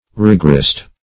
Search Result for " rigorist" : The Collaborative International Dictionary of English v.0.48: Rigorist \Rig"or*ist\, n. [Cf. F. rigoriste.]